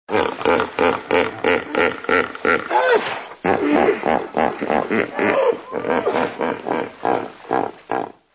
Они выражают их с помощью рева, иногда звуки похожи на конское ржание или хрюканье. Рев бегемотов очень громкий, разносится далеко по африканским просторам.
begemot-ili-gippopotam-hippopotamus-amphibius.mp3